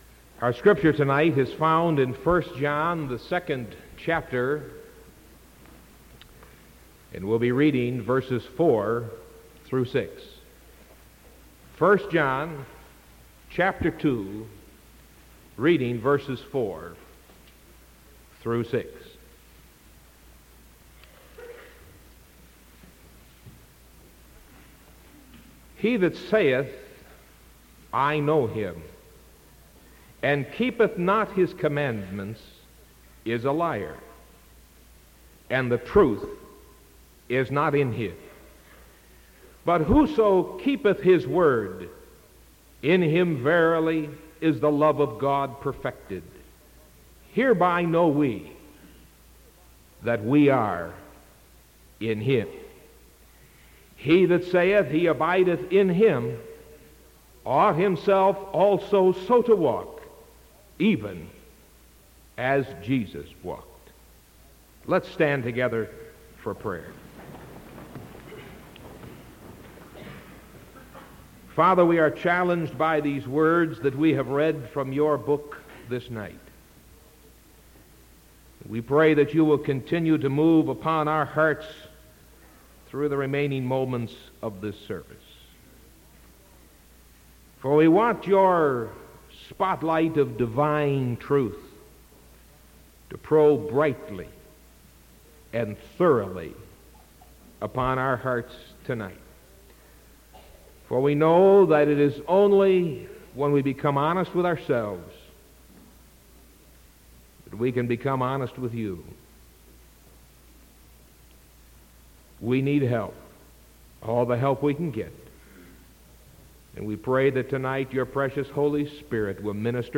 Sermon April 13th 1975 PM